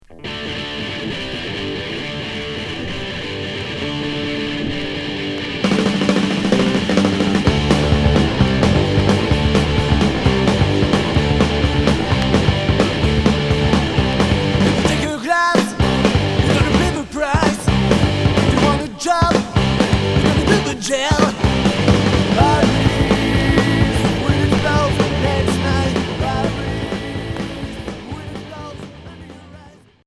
Punk